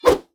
fire2.wav